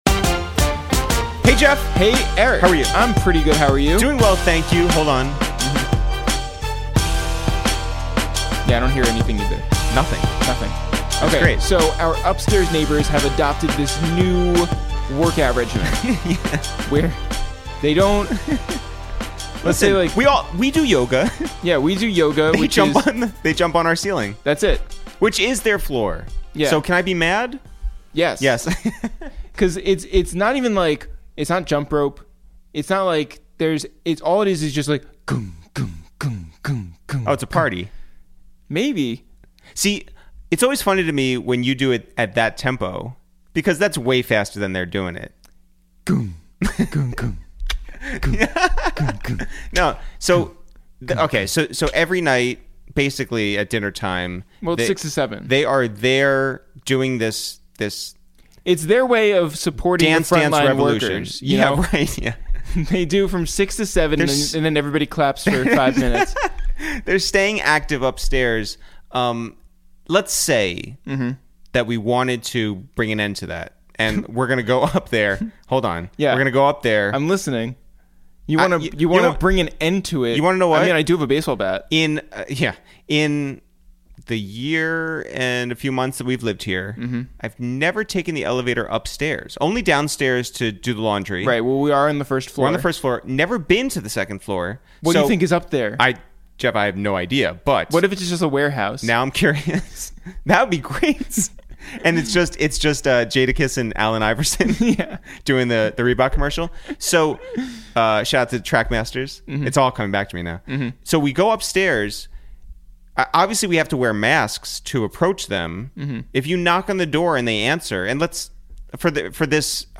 Today on Episode 49 of Quarantine Radio, we make calls from our Upper West Side apartment to check in on super producer TM88, who discusses his and Southside's new single with Future, Young Thug and Moneybagg Yo, his classic work on Gucci Mane's World War mixtape series, his c...